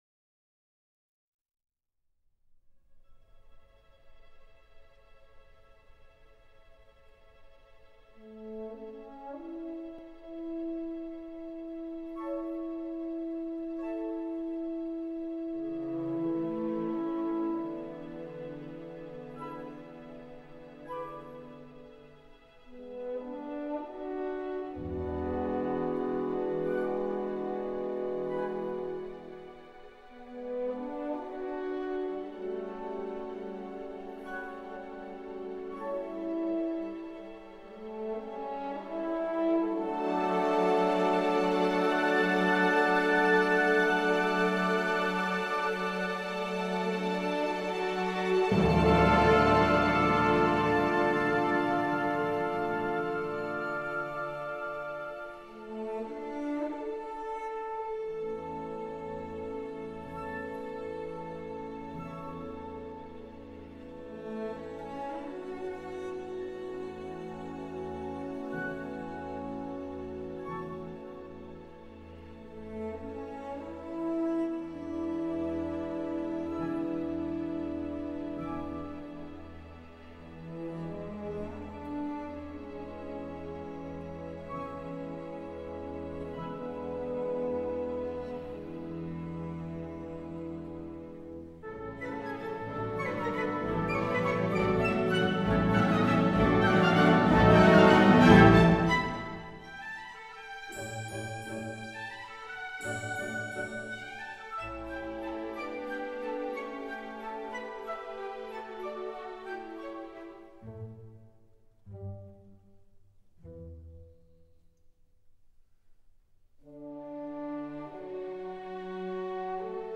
waltz.mp3